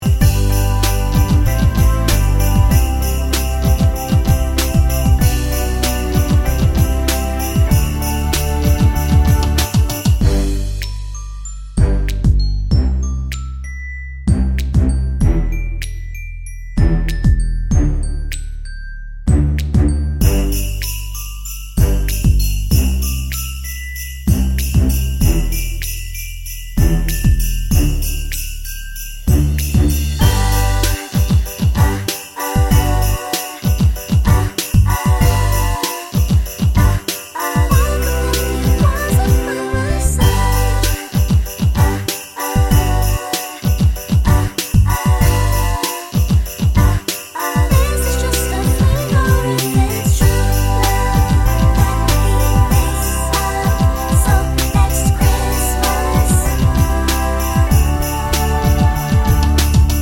no Backing Vocals Christmas 3:23 Buy £1.50